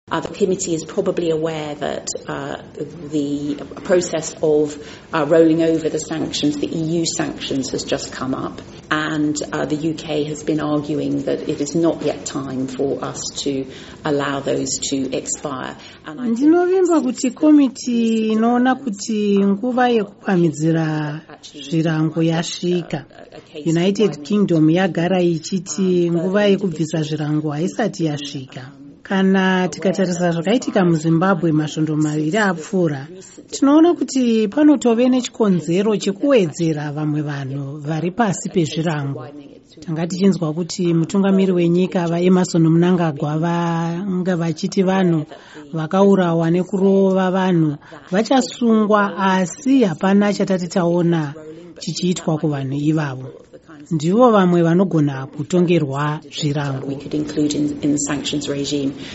Hurukuro naAmai Harriett Baldwin